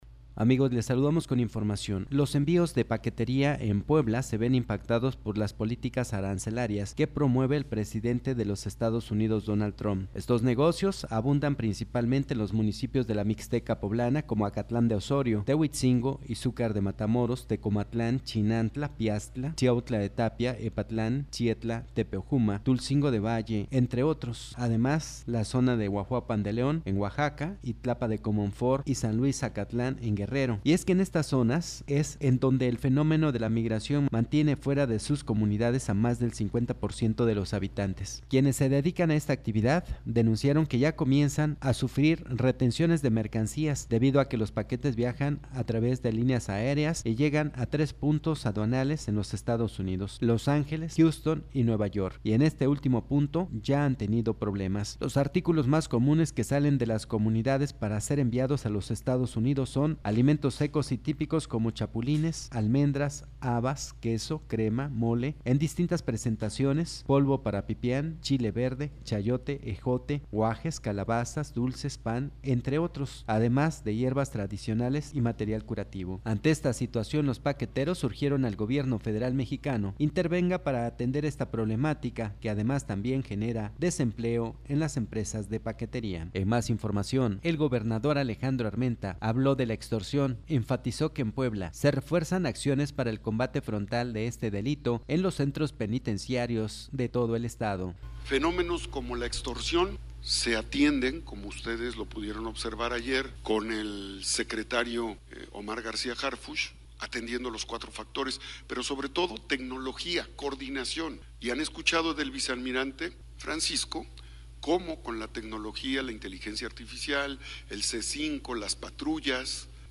– Presentamos el reporte sobre empresas de paquetería comienzan a registrar problemas en el traslado de mercancías que, van con destino a los Estados Unidos. Autoridades poblanas buscan combatir la extorsión en Centros Penitenciarios y extorsionadores buscan sorprender a víctimas que sufren robo de sus vehículos.